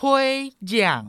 pue rhiangˆ
pue⁺ rhiangˆ